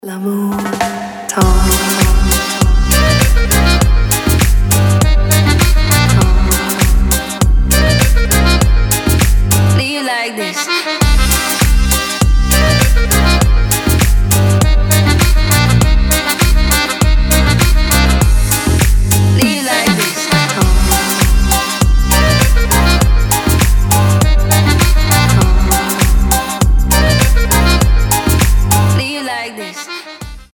deep house
женский голос
чувственные
аккордеон
Чувственный французский дип с игрой на аккордеоне